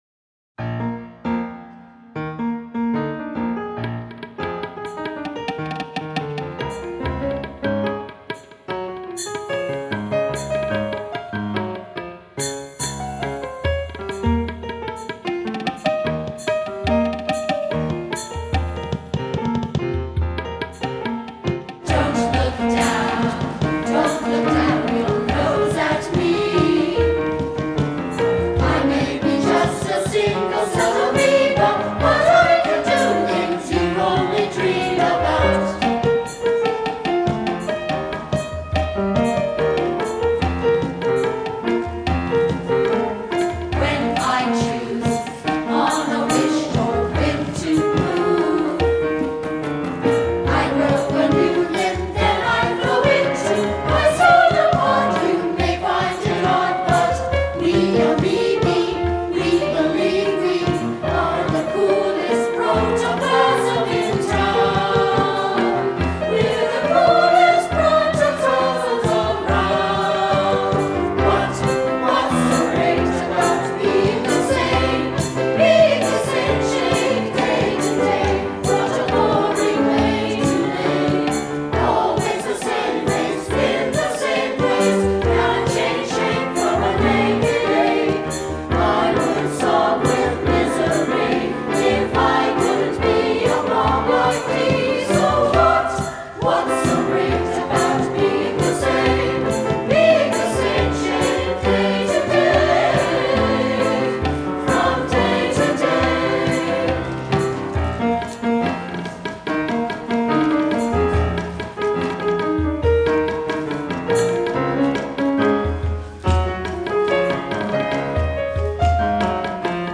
Below you can hear the 2007 Festival Chorus performing Lifetime: Songs of Life and Evolution.